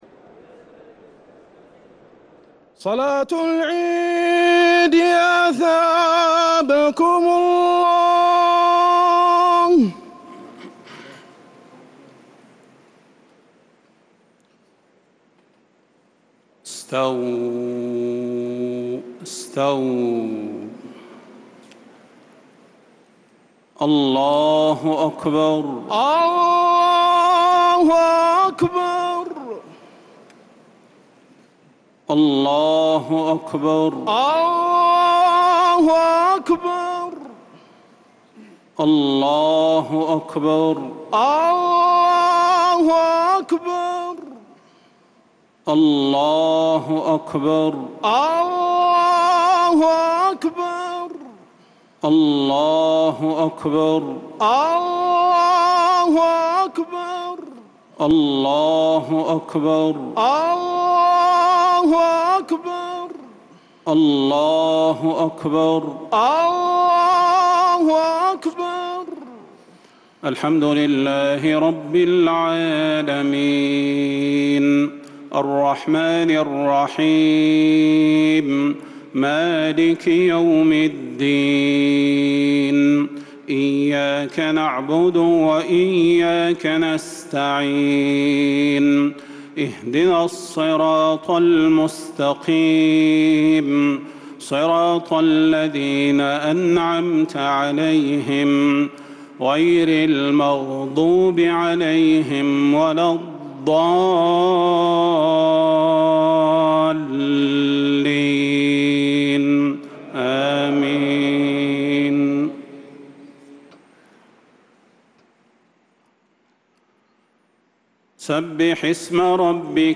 صلاة عيد الأضحى 1446هـ سورتي الأعلى و الغاشية | Eid al-Adha prayer Surat Al-a’ala and Al-Ghashiya 6-6-2025 > 1446 🕌 > الفروض - تلاوات الحرمين